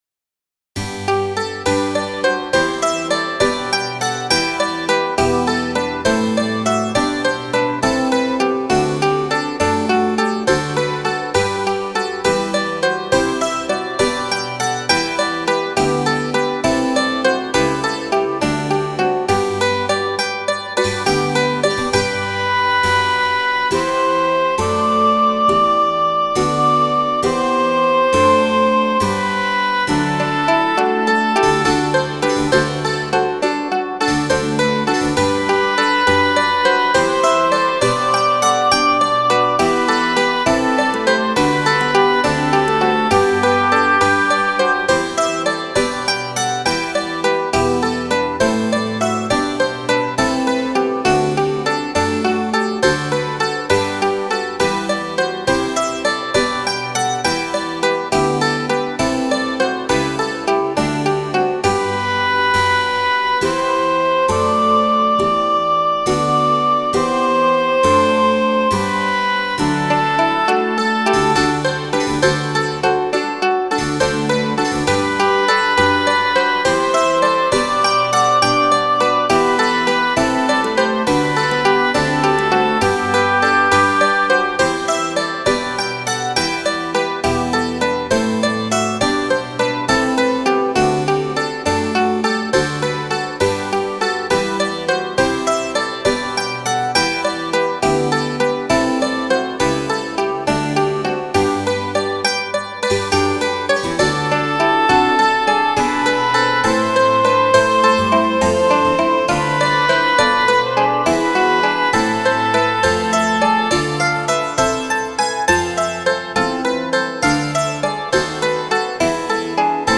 für Solo und Zupforchester (gem. Chor und Continuo ad lib.)